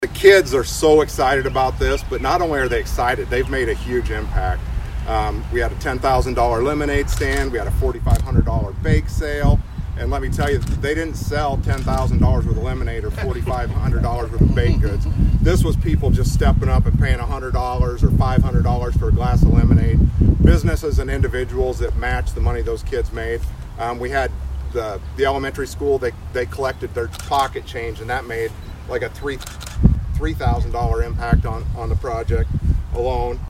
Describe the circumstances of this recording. Groundbreaking ceremony held for the Atlantic SplashPad Project